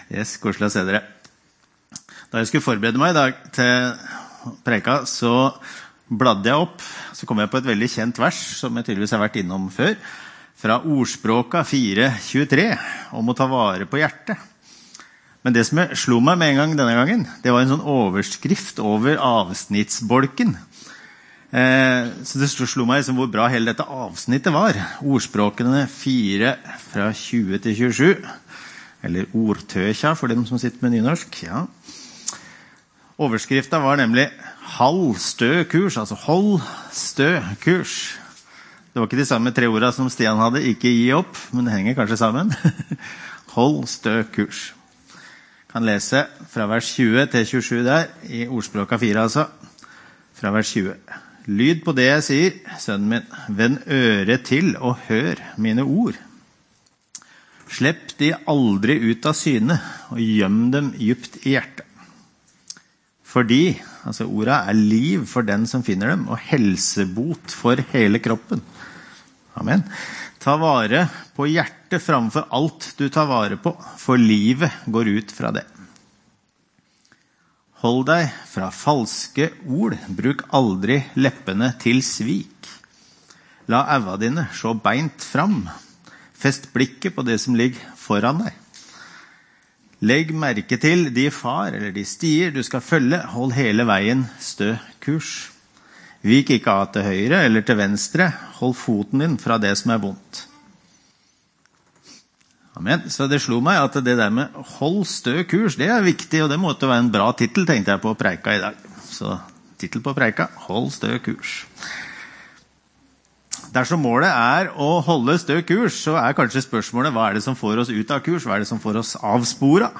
Opptak av tale
Tid: 18. januar 2026 kl. 11 Stad: No12 – Håkon den godes g. 12, Levanger